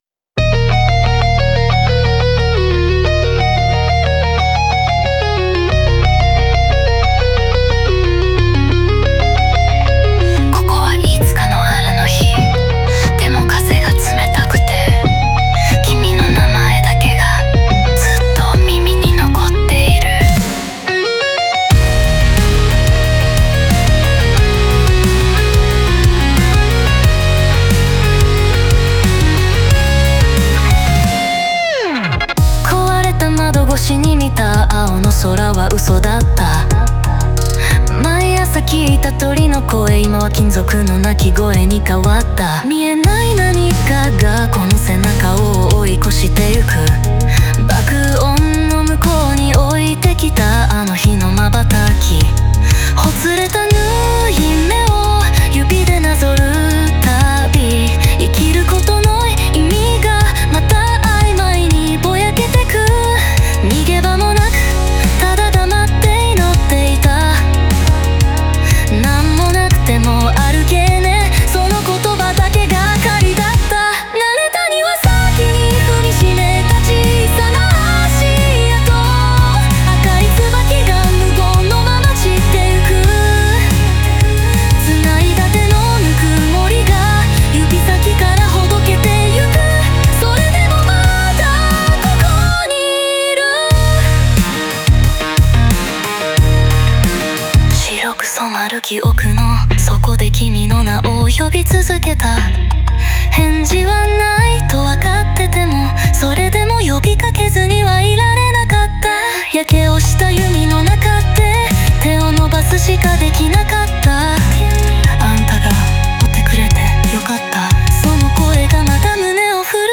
音楽的には、メタルコアの激しいテンポと静寂の対比を活かし、内面の葛藤と希望をダイナミックに表現しています。